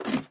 ladder1.ogg